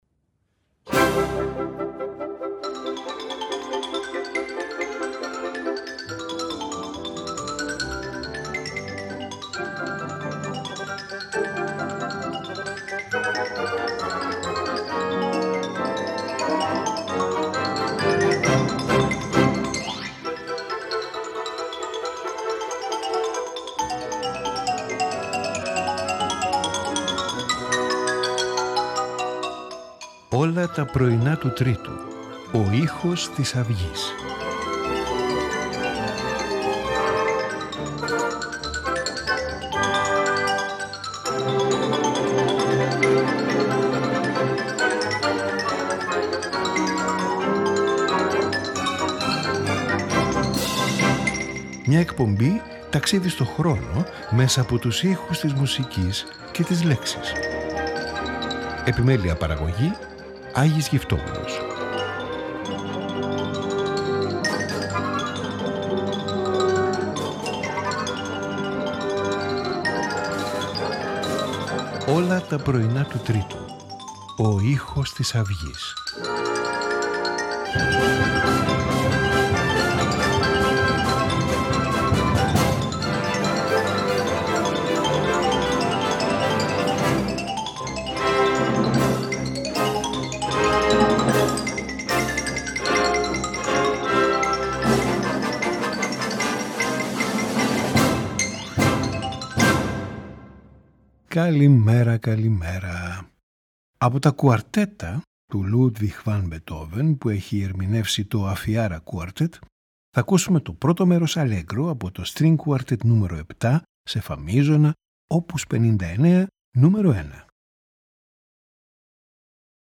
String Quartet No.7 in F major
Piano Quintet in A major
Sonata for Flute and Piano No.5 in E major
Flute Concerto in F major